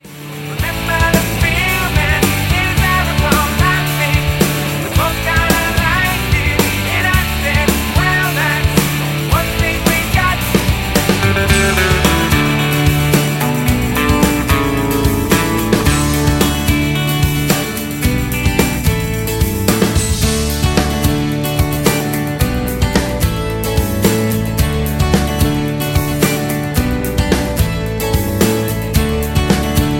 Backing track files: 1990s (2737)